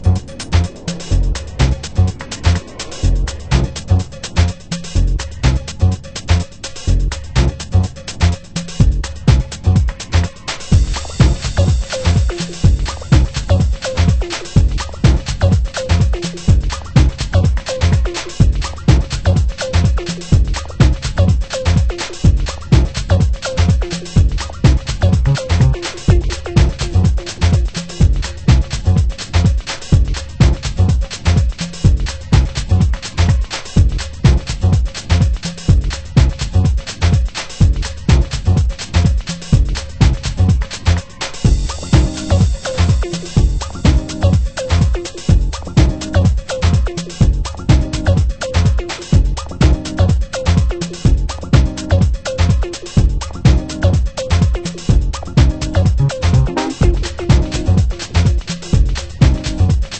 JAZZY